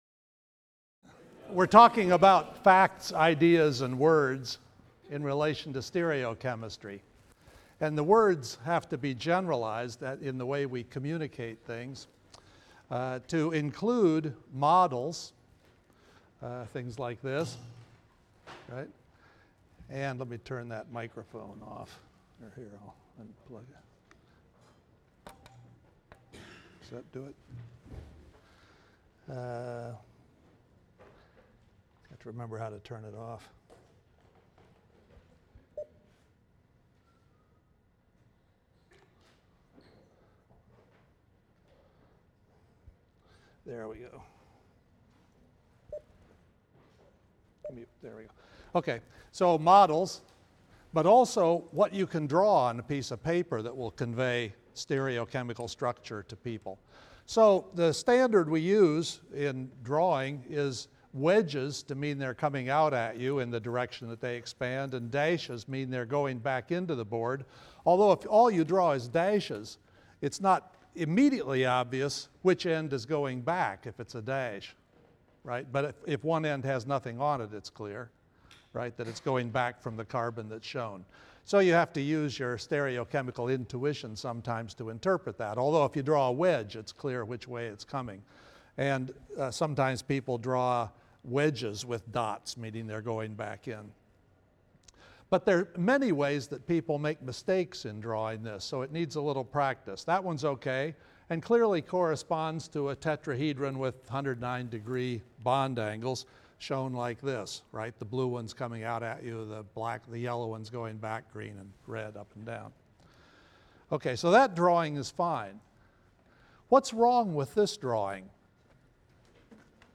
CHEM 125a - Lecture 27 - Communicating Molecular Structure in Diagrams and Words | Open Yale Courses